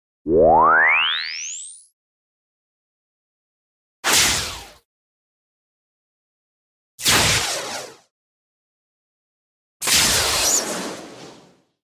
Звуки космоса
Пространственный рокот, космическое жужжание